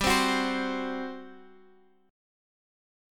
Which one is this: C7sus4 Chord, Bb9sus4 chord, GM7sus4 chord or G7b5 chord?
GM7sus4 chord